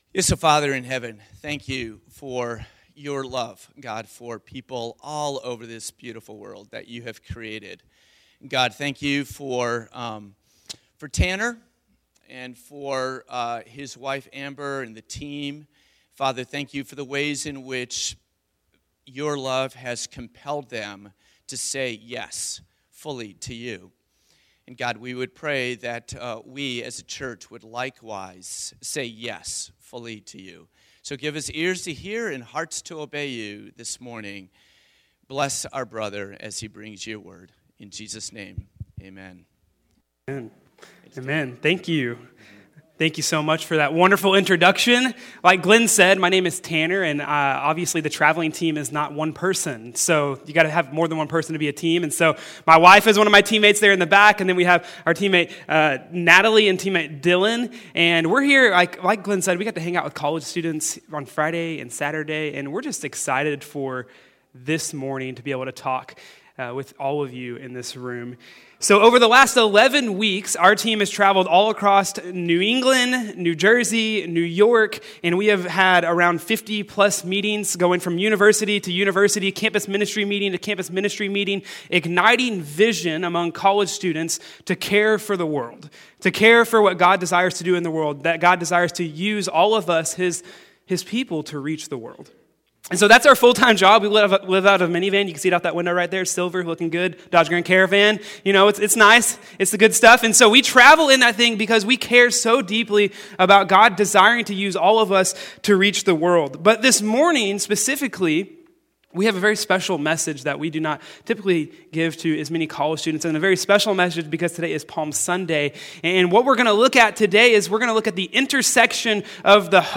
Palm Sunday Message